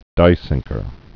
(dīsĭngkər)